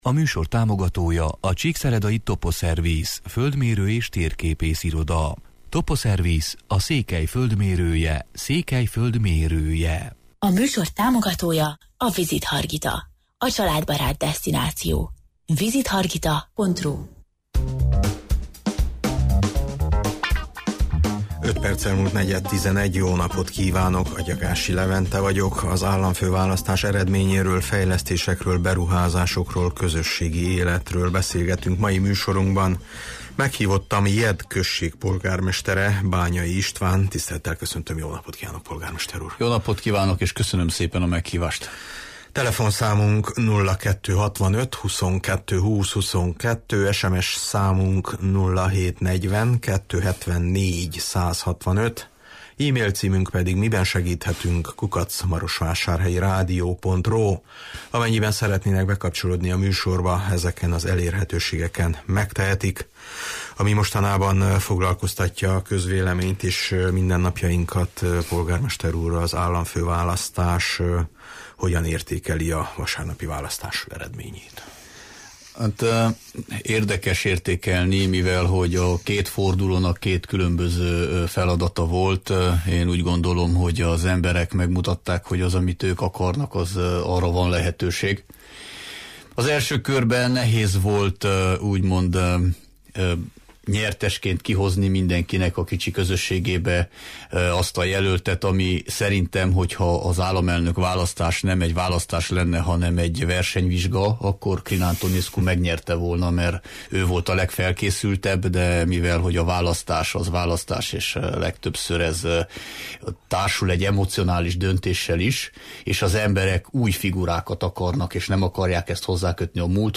Meghívottam Jedd község polgármestere, Bányai István: